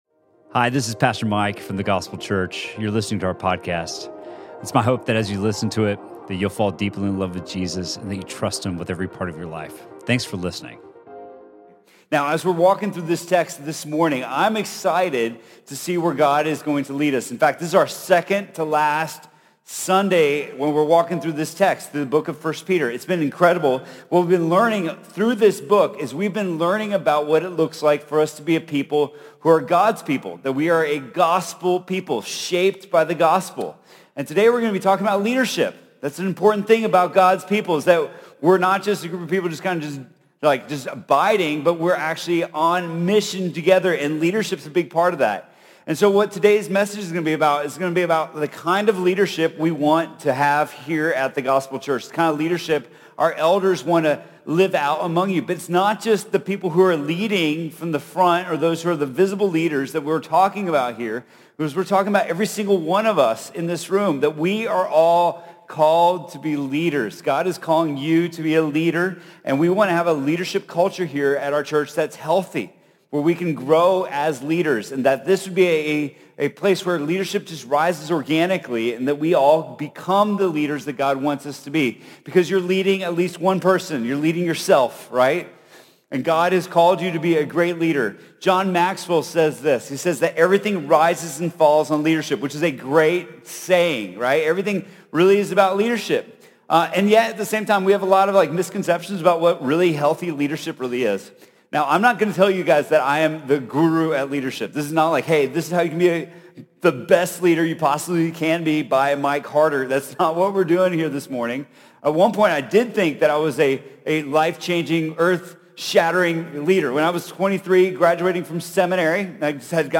Sermon from The Gospel Church on February 3rd, 2019.